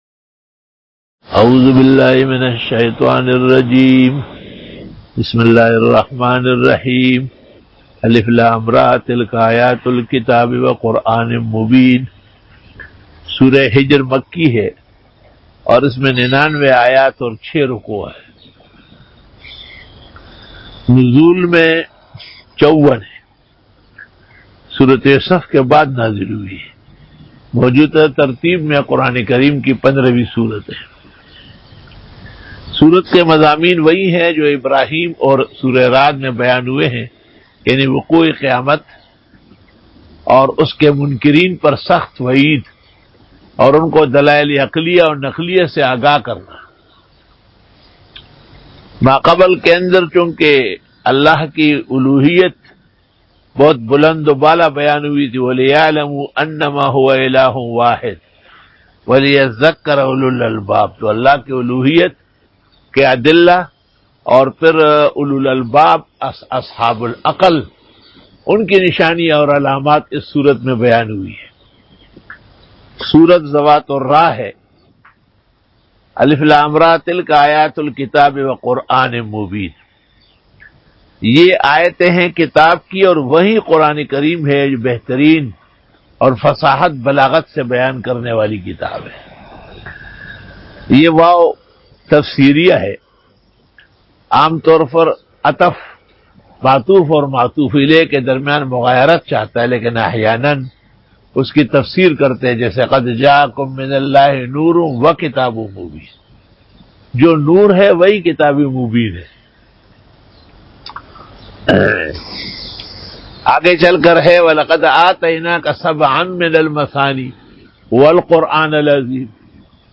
Bayan by